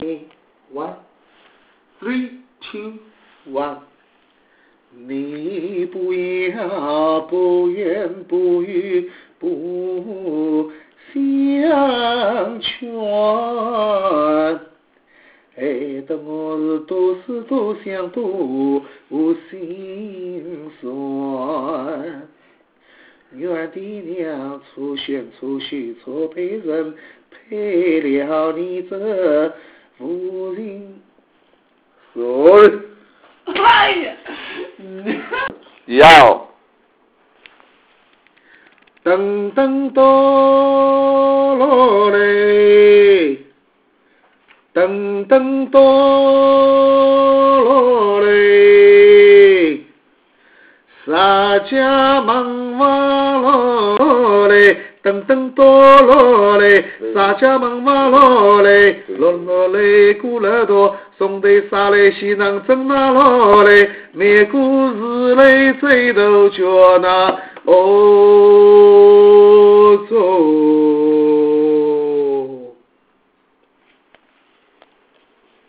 Children song performed by local people
Children song.wav